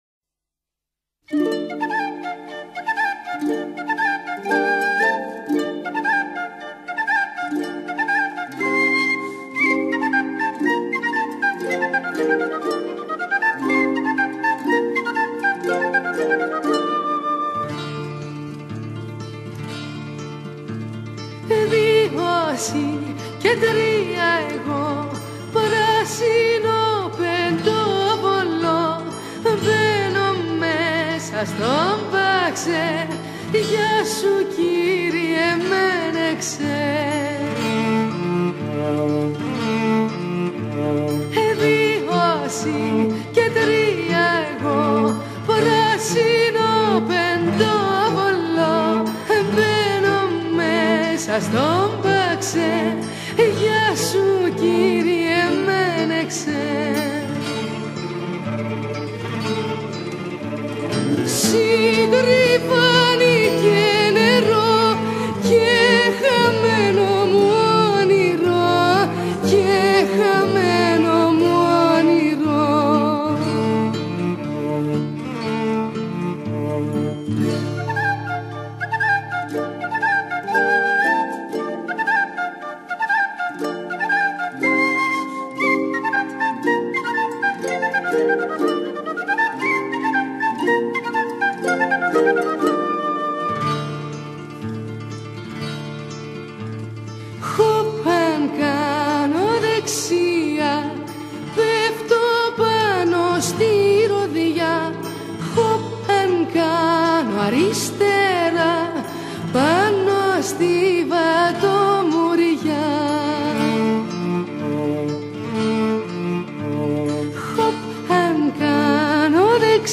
Μπορείτε και να χορέψετε ακούγοντας το ποίημα μελοποιημένο.